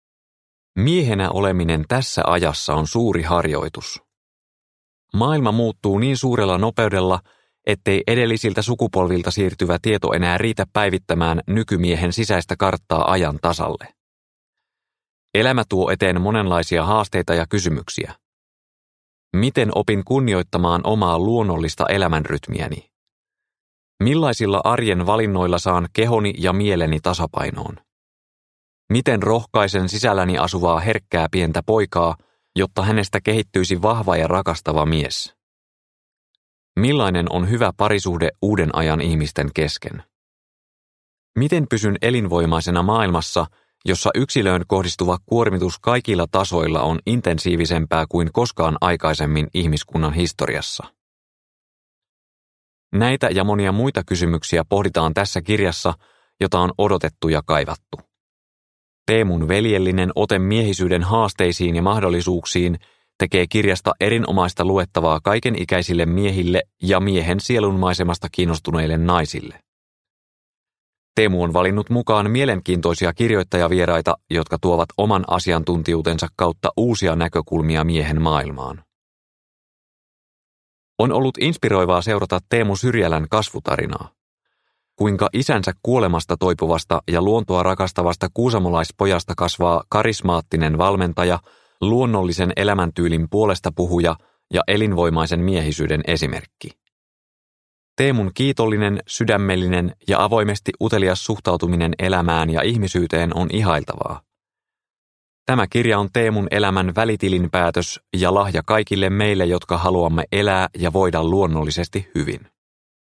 Elinvoimaisen miehen kirja – Ljudbok – Laddas ner